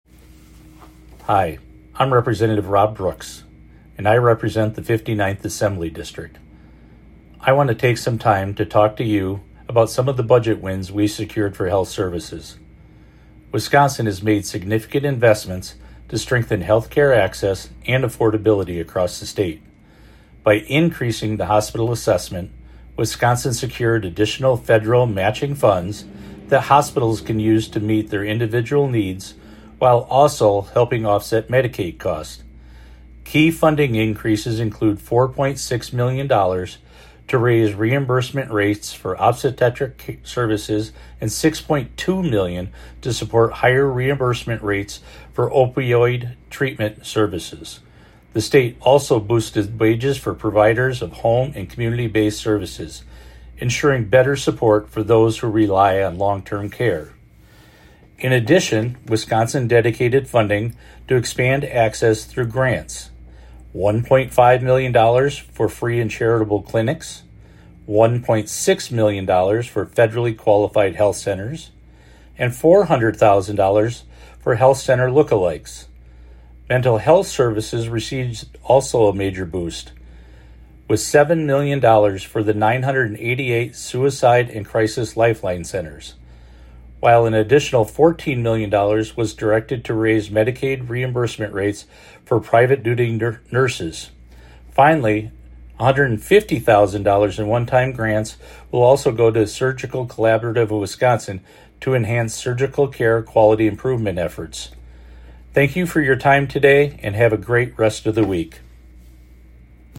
Weekly GOP radio address: Budget wins in health services